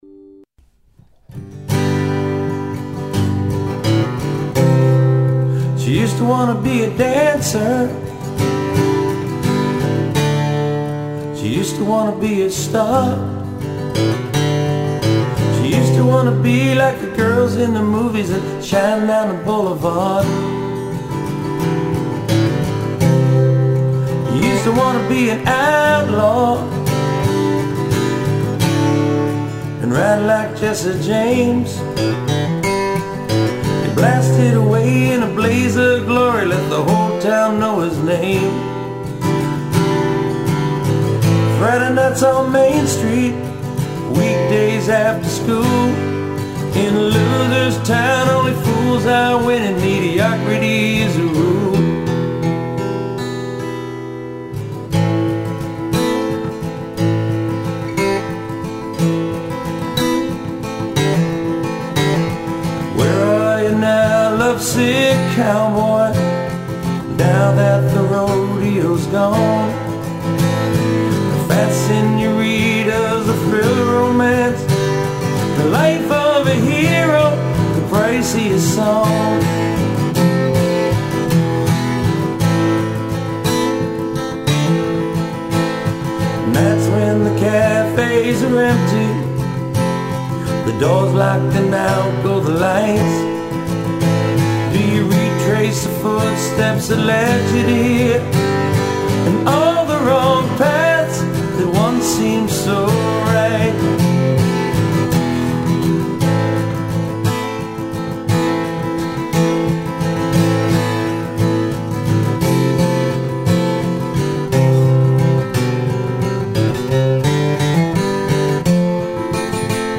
All solo performances